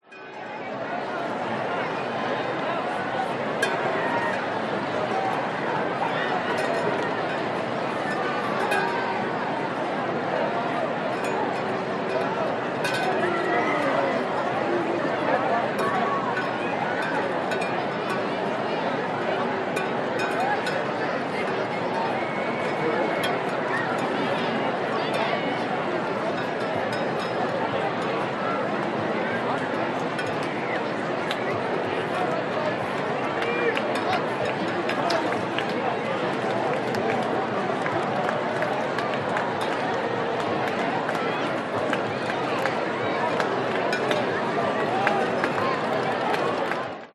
Шум толпы на стадионе регби